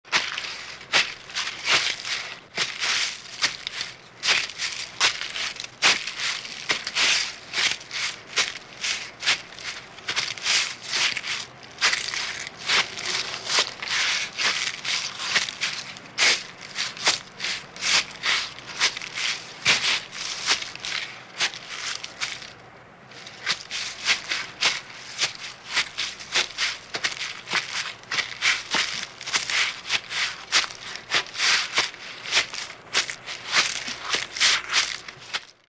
Звуки сухих листьев
На этой странице собраны звуки сухих листьев: шелест под ногами, порывы ветра, играющего с опавшей листвой.